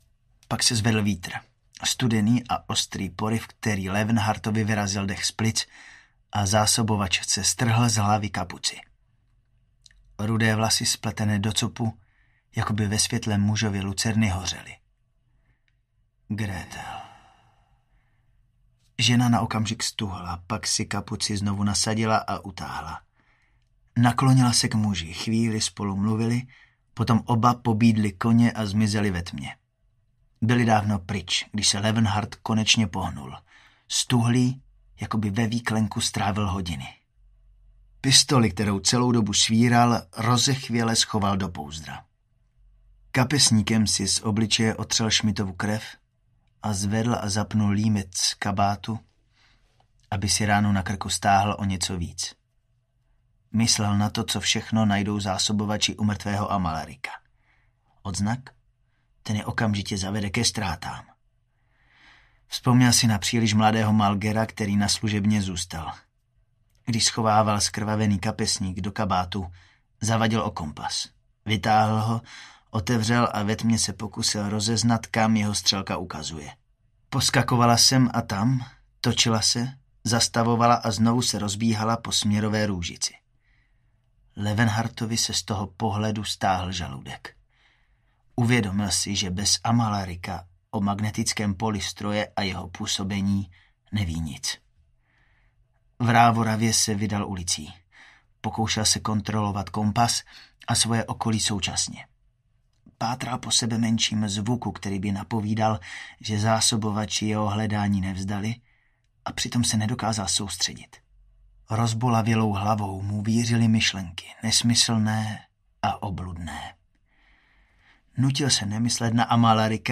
Město v oblacích audiokniha
Ukázka z knihy